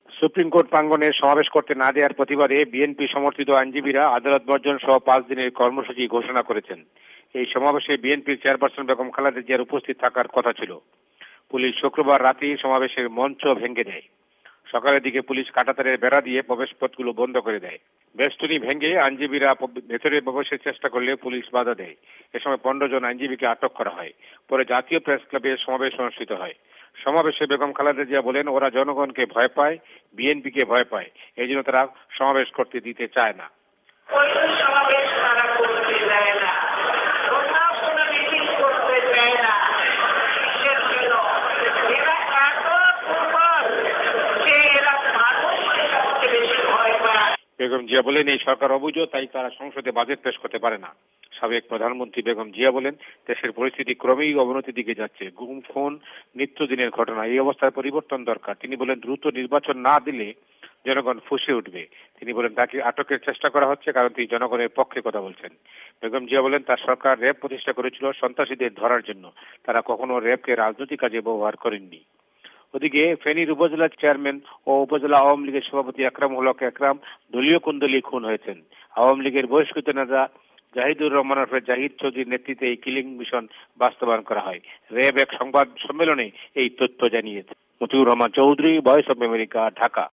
ভয়েস অব আমেরিকার ঢাকা সংবাদদাতাদের রিপোর্ট